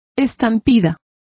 Complete with pronunciation of the translation of stampedes.